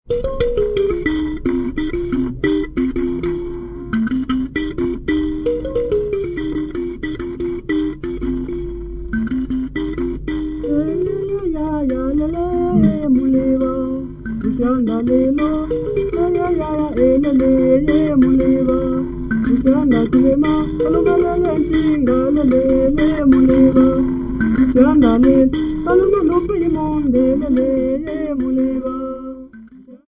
Tracey, Hugh
Folk music--Africa
Field recordings
Indigenous self-delectative folk song with singing accompanied by a 10 note chisashi with soundholes.